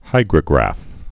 (hīgrə-grăf)